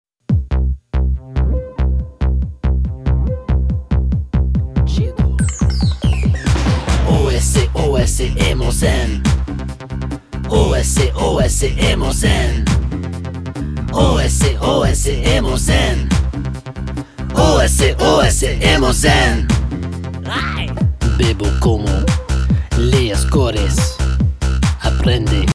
Spanish CD or Album Download